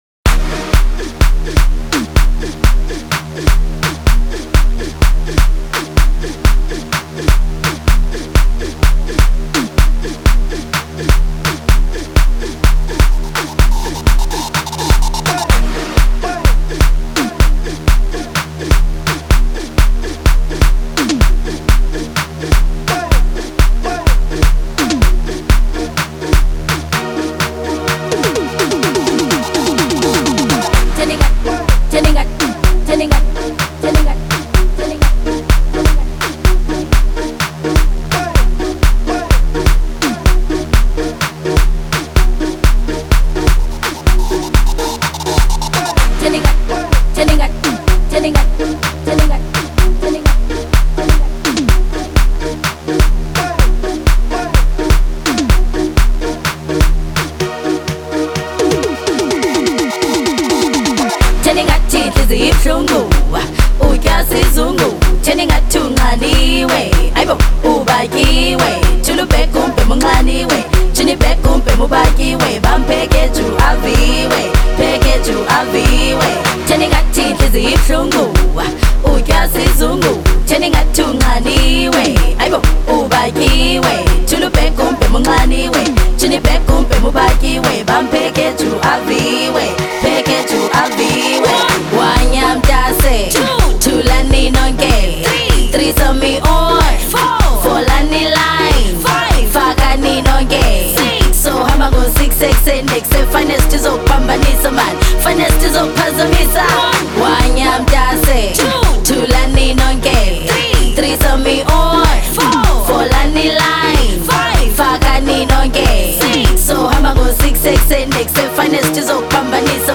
gqom